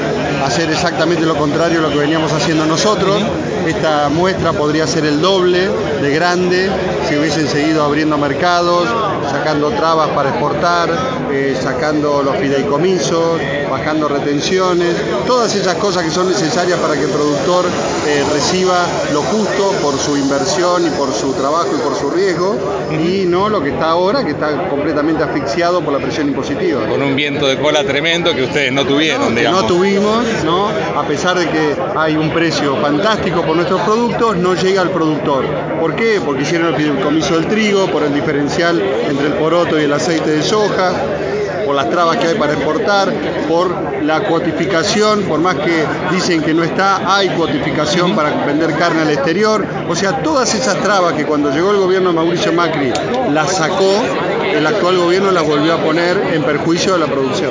Luis Etchevehere, ex presidente de la Sociedad Rural Argentina y ex ministro de Agricultura de la gestión Macri, visitó Expoagro y criticó fuertemente la política del Gobierno de Alberto Fernández.